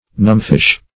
Numbfish \Numb"fish`\, n. (Zool.)